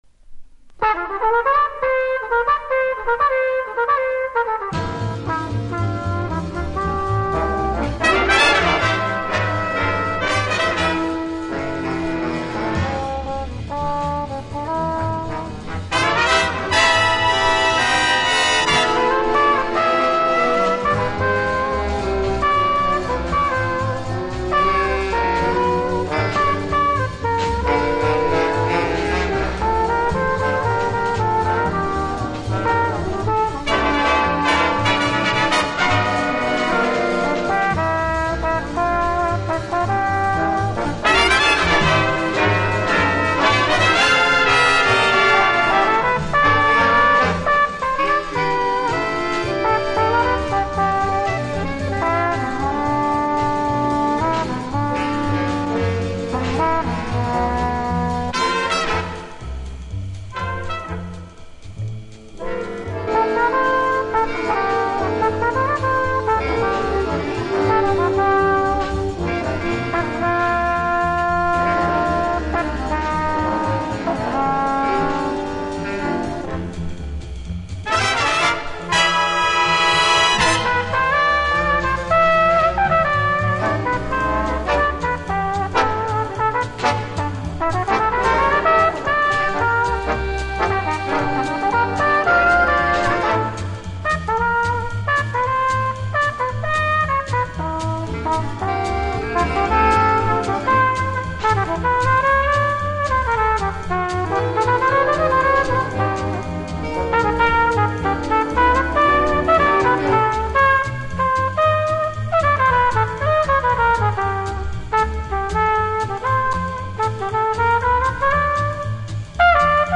オーケストラ